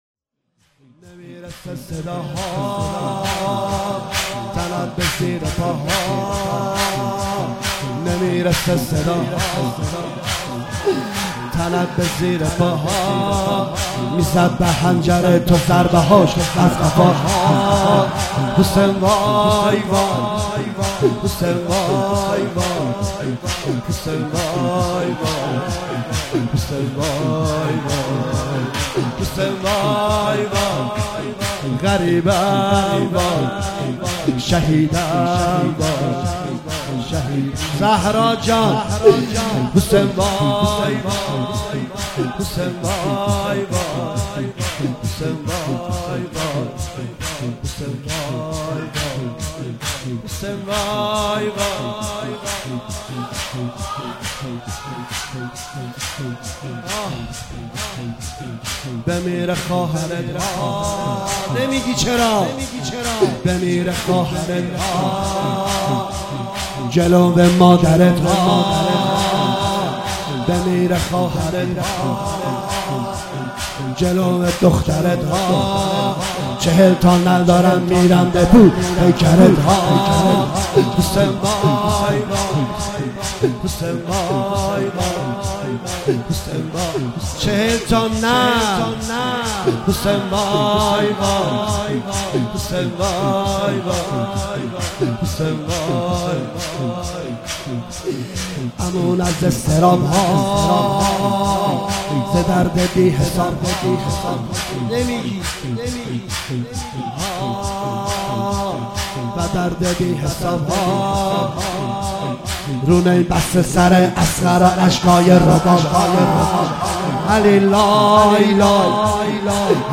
12 بهمن 97 - هیئت مکتب الحسین - شور گودال